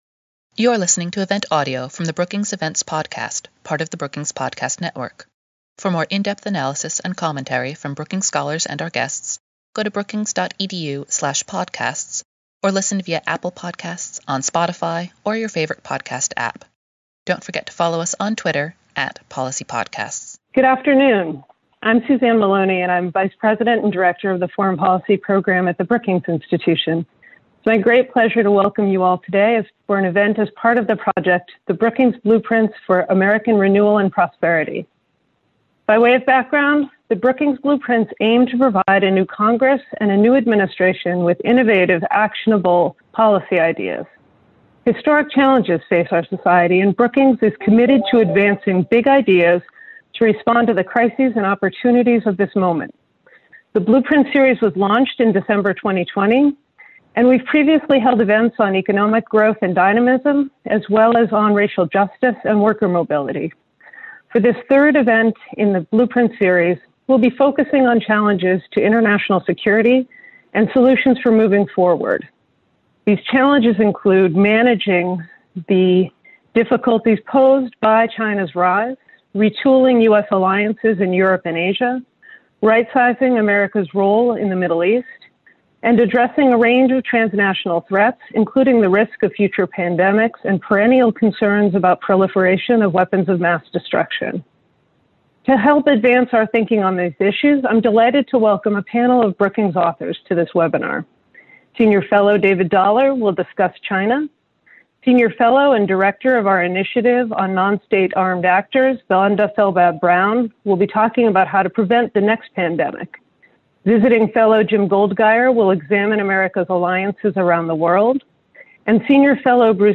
Foreign policy experts discussed their recommendations for how to best handle the international security challenges facing the world today.